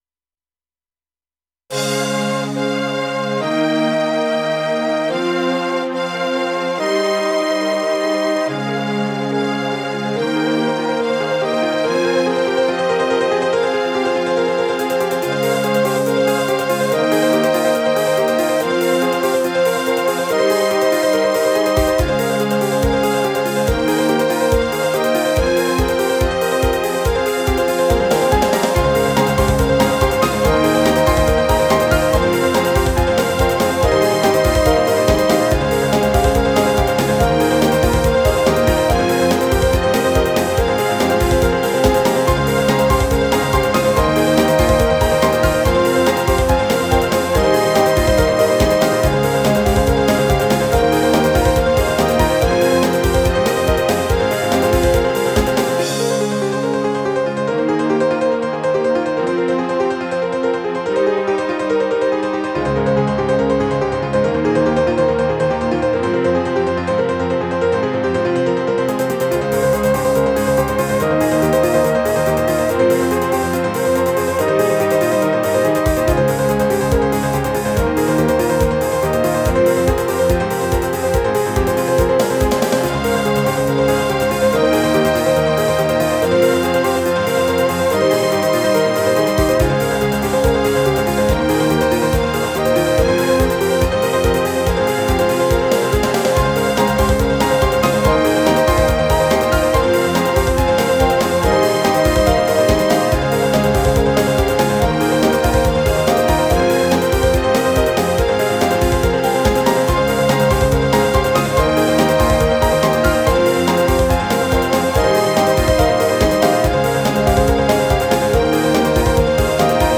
由Roland Sound Canvas 88Pro实机录制；MP3采样率44.1KHz，码率192Kbps。